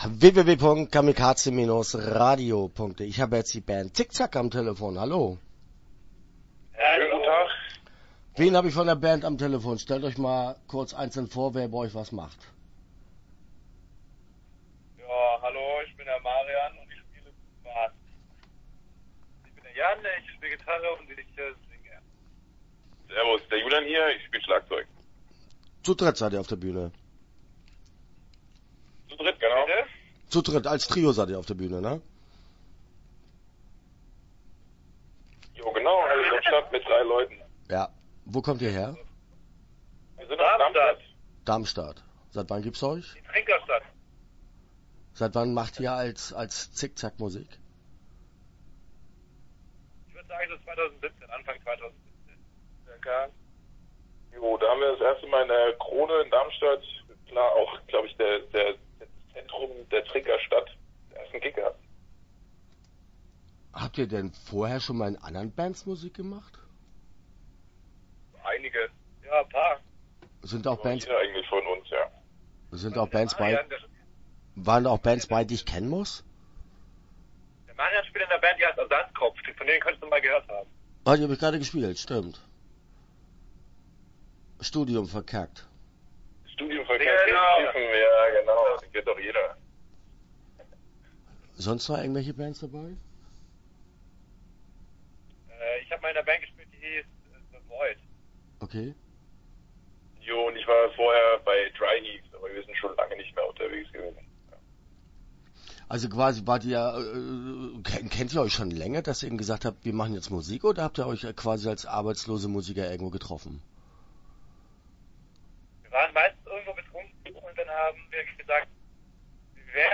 Start » Interviews » Zik Zak